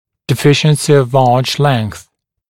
[dɪ’fɪʃnsɪ əv ɑːʧ leŋθ][ди’фишнси ов а:ч лэнс]недостаток длины зубного ряда, недостаточная длин ортодонтической дуги